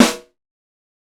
TC2 Snare 33.wav